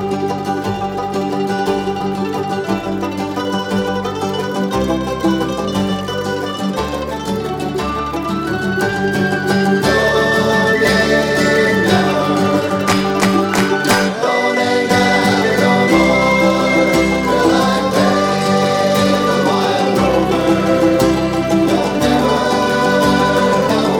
Faster With Claps and No Backing Vocals Irish 2:42 Buy £1.50